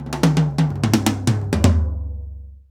Index of /90_sSampleCDs/Roland L-CDX-01/TOM_Rolls & FX/TOM_Tom Rolls
TOM TOM R05R.wav